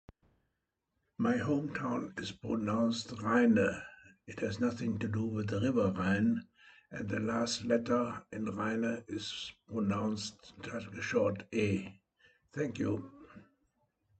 "Rheine" (pronounciation).
rheine-pronounce.mp3